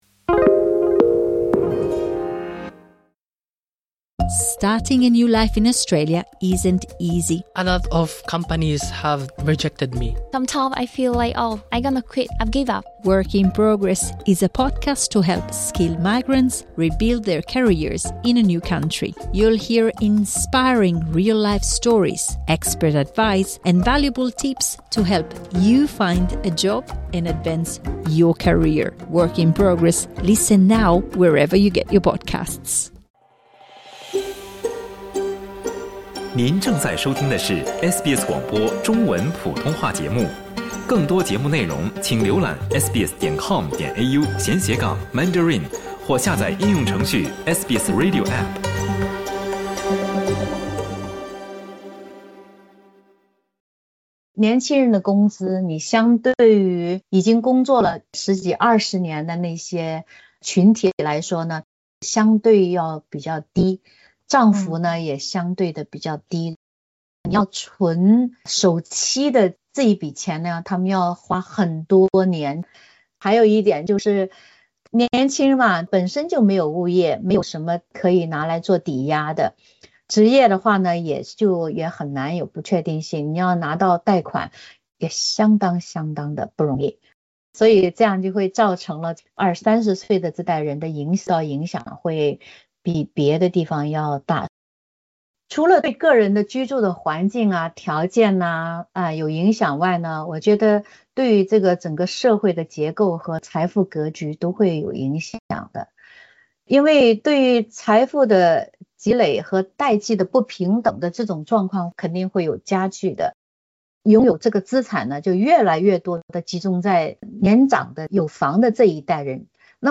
她接受SBS普通话节目采访时指出，其显著特点之一是“供需结构上的失衡”。